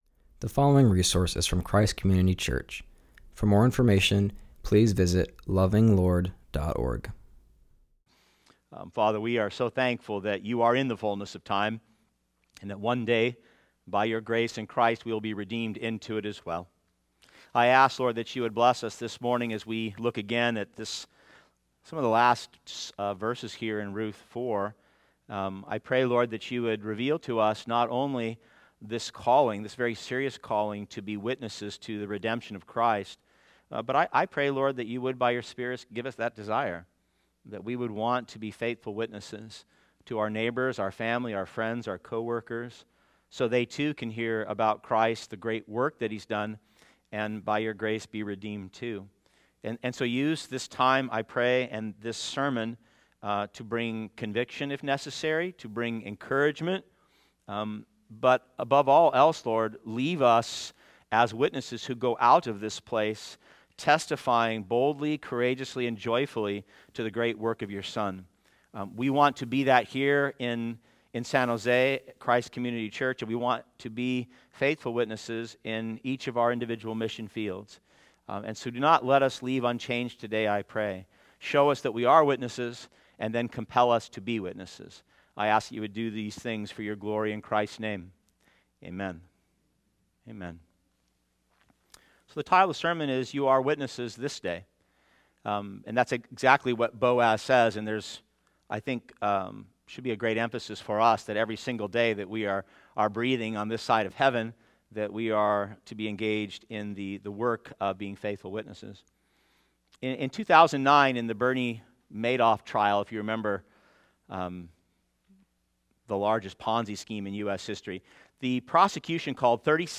preaches on Ruth 4:9-12.